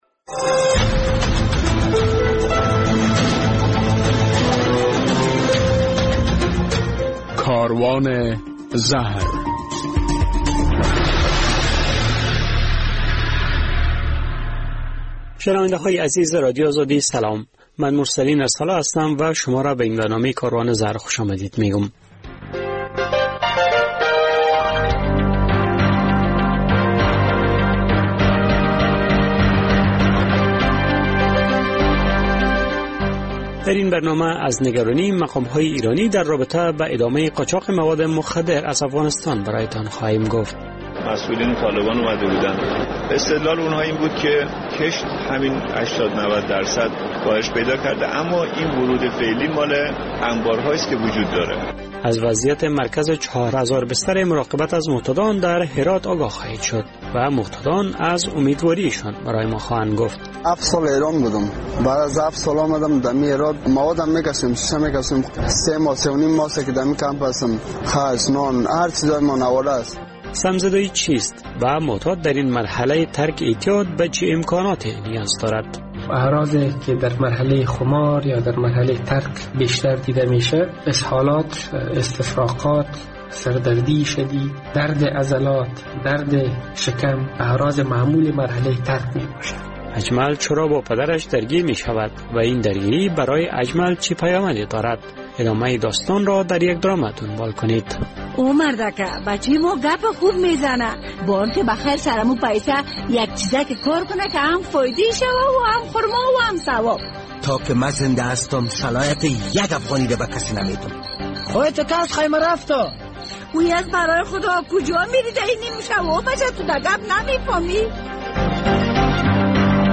در این برنامه کاروان زهر از نگرانی ایران در رابطه به ادامه قاچاق مواد مخدر از افغانستان برای تان خواهیم گفت، در یک گزارش می‌شنوید که معتادان در مرکز مراقبت از معتادان در هرات انتظار دارند که با مرخص شدن از این مرکز برای شان زمینه کار فراهم شود، در مصاحبه با یک متخصص از وی پرسیدیم که مرحله...